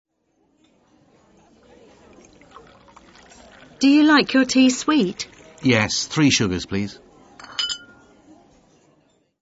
British English